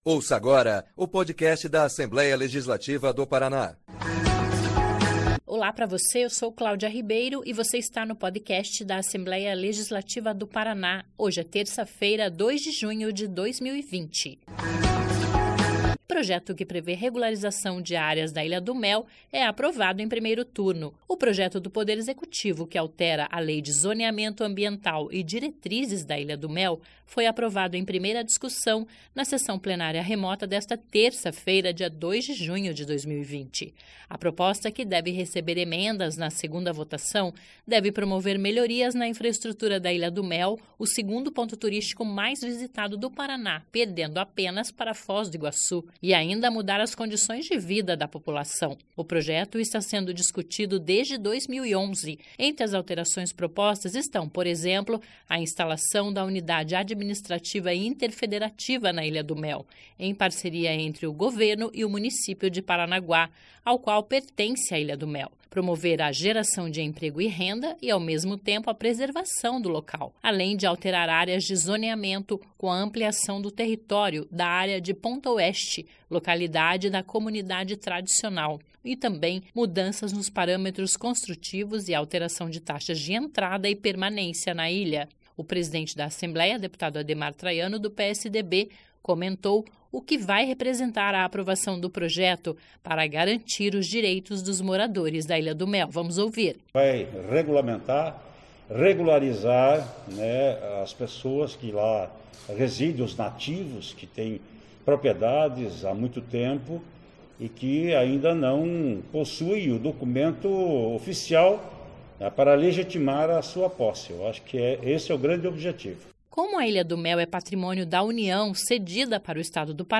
O presidente da Assembleia, deputado Ademar Traiano (PSDB), comentou o que vai representar a aprovação do projeto para garantia dos direitos dos moradores da Ilha do Mel.
(Sonora)